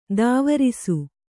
♪ dāvarisu